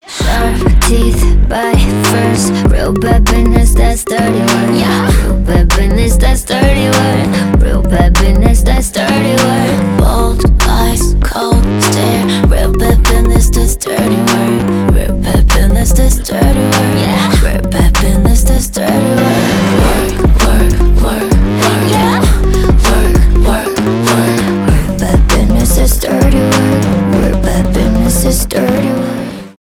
k-pop , танцевальные , rnb